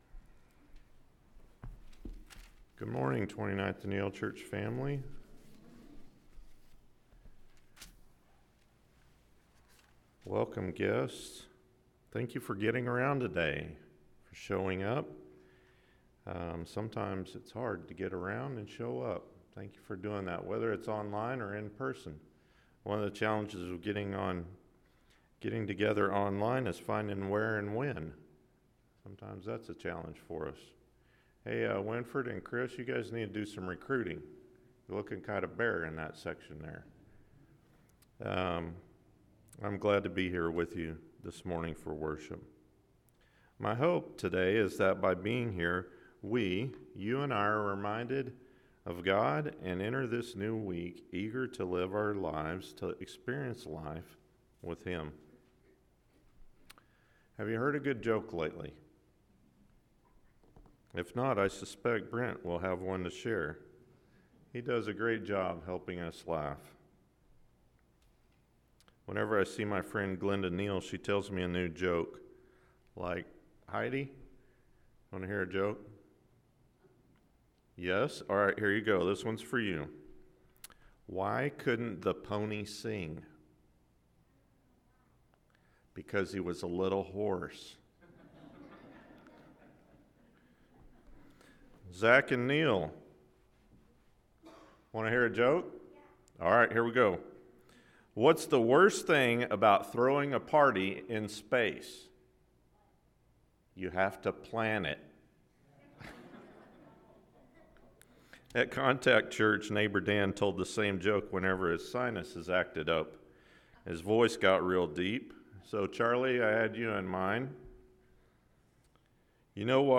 Kingdom Stories: The Sower Sermon – Matt 13:1-23 – Sermon
KingdomStories-TheSowerSermon-Matt13-1-23AM.mp3